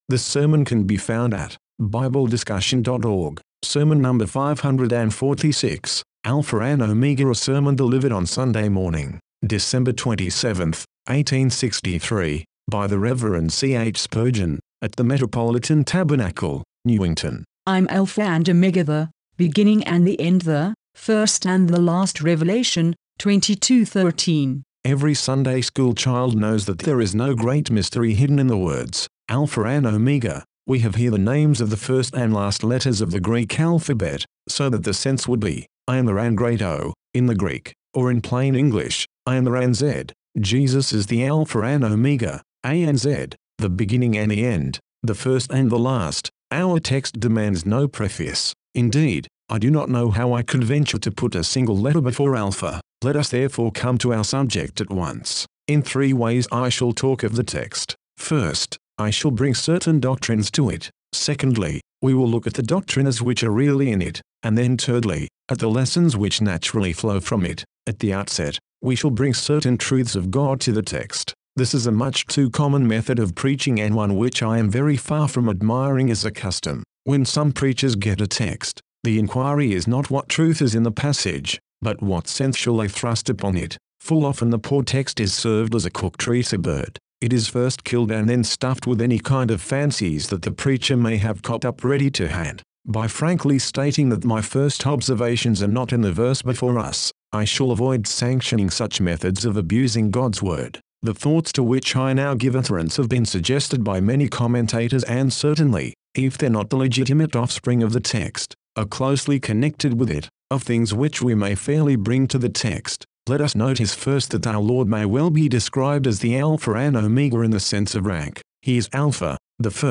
Sermon #546 – Alpha And Omega